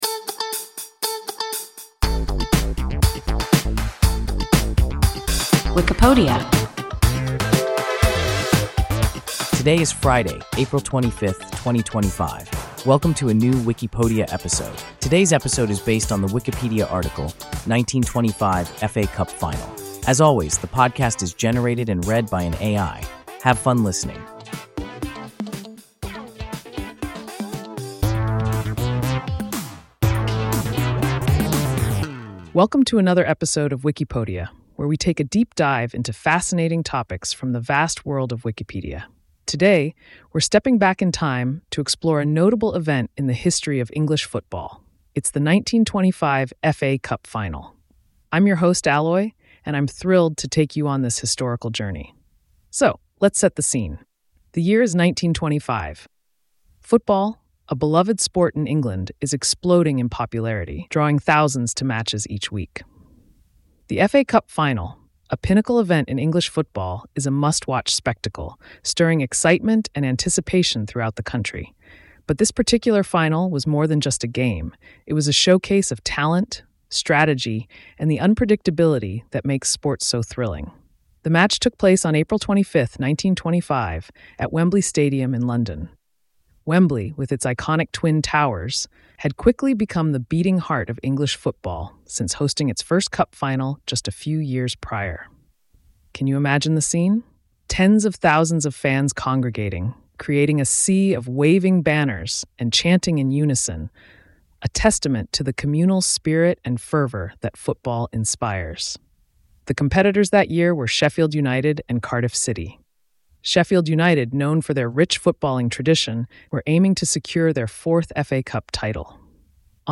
1925 FA Cup final – WIKIPODIA – ein KI Podcast